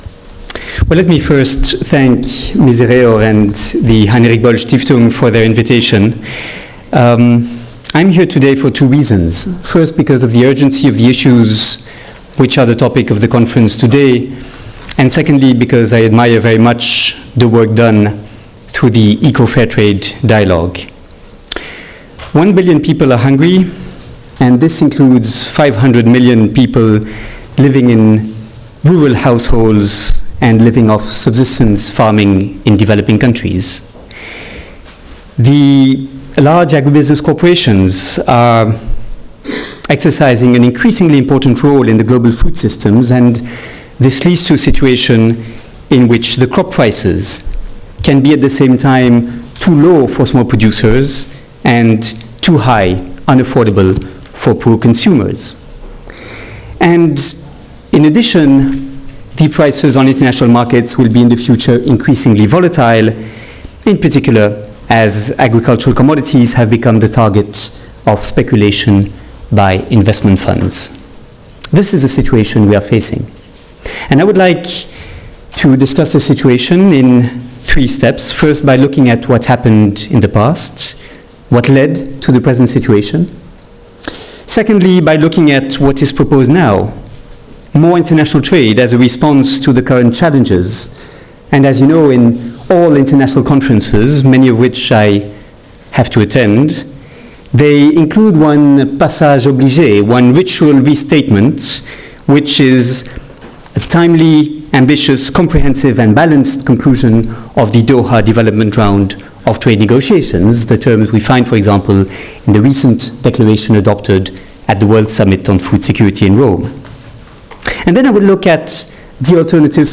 Misereor und die Heinrich-Böll-Stiftung luden am 12. Januar 2010 in ihrer gemeinsamen Konferenz dazu ein, die Regeln und Ströme des internationalen Agrarhandels im Spannungsfeld der großen globalen Krisen (Klima-, Ernährungs- und Finanzkrise) zu überdenken und neu zu definieren.
Keynote von Olivier de Schutter